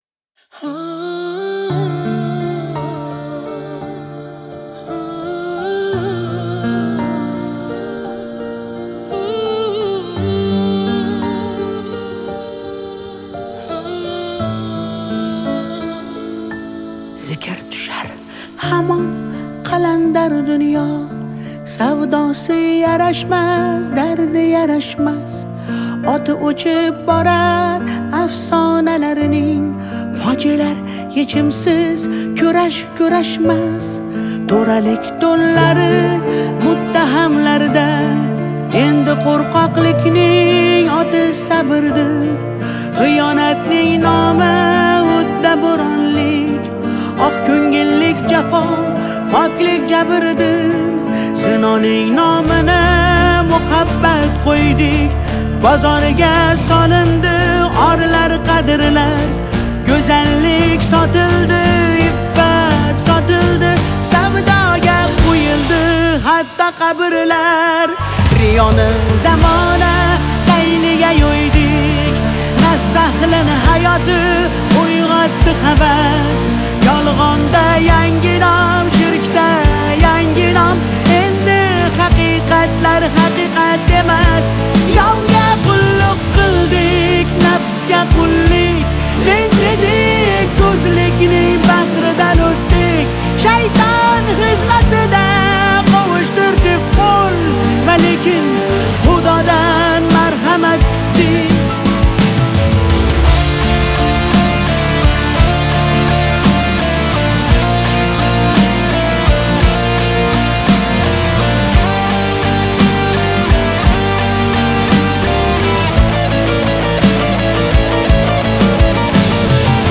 Узбекские песни